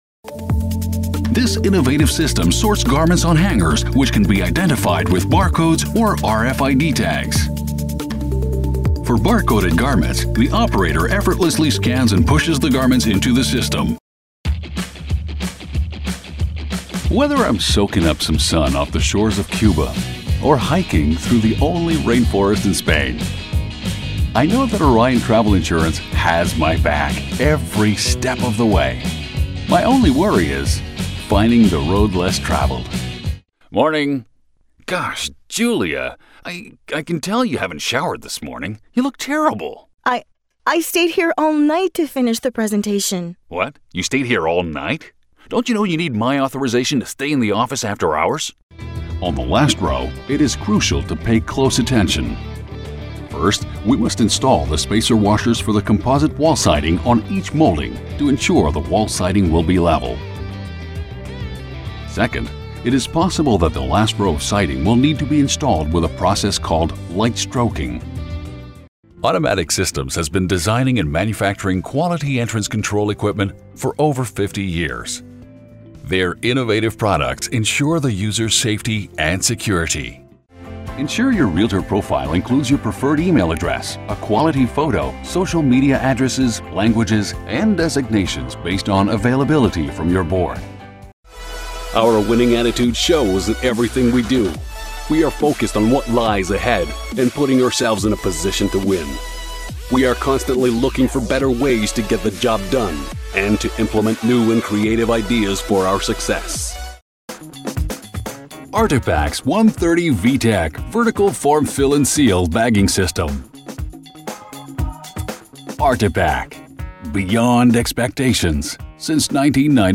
French Canadian Voice Talent